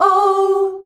OUUH  G.wav